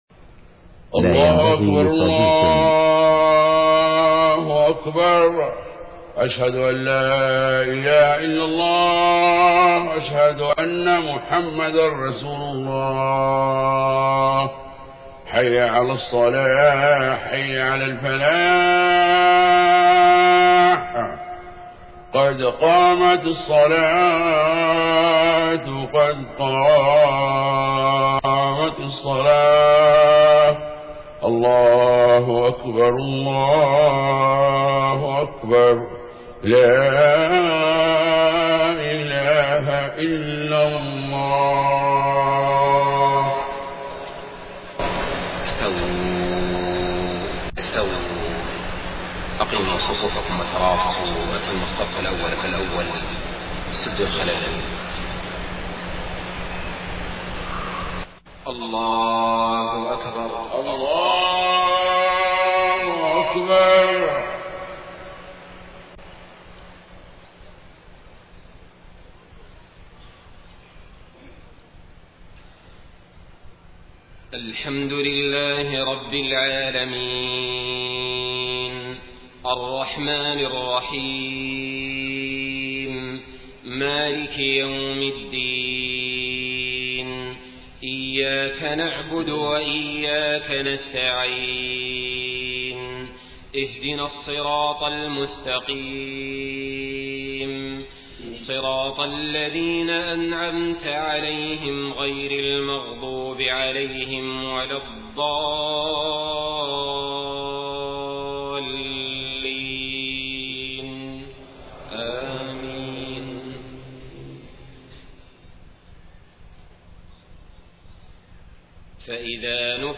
صلاة العشاء 2 ربيع الأول 1431هـ خواتيم سورة المؤمنون 101-118 > 1431 🕋 > الفروض - تلاوات الحرمين